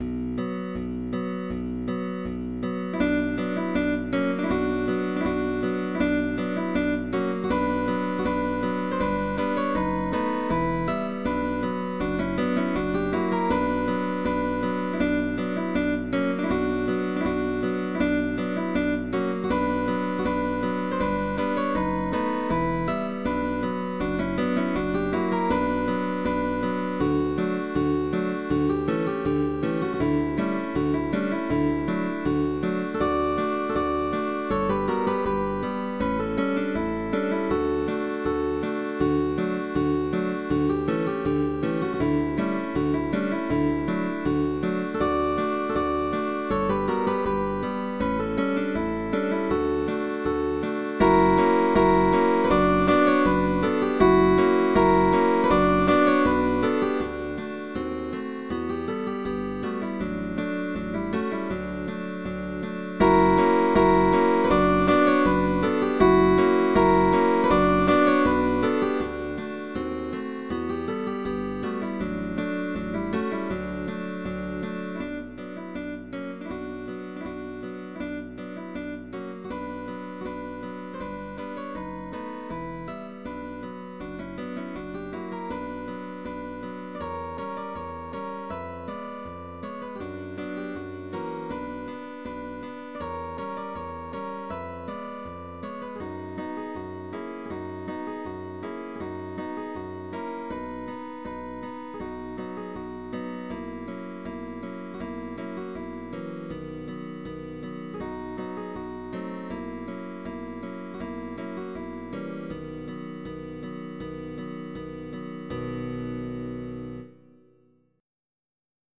guitar trio
GUITAR TRIO